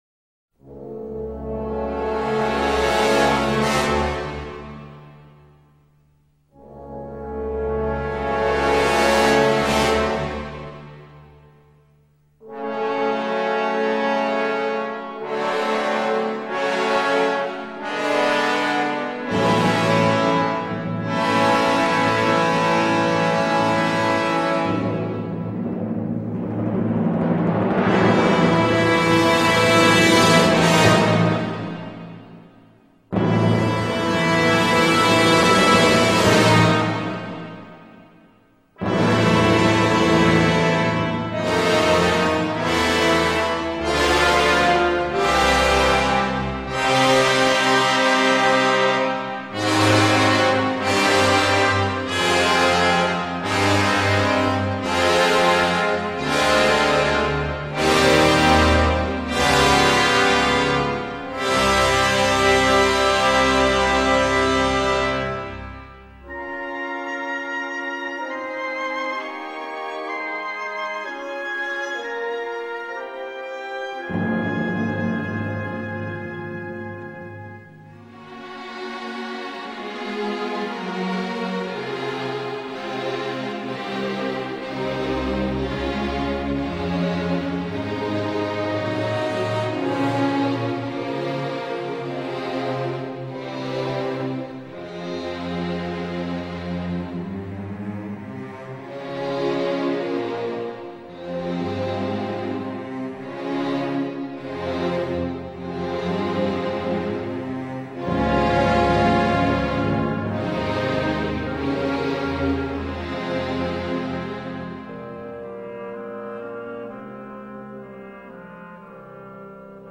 La vibración de la de Sanat Kumara fue captada por Jan Sibelius en el poema sinfónico Finlandia.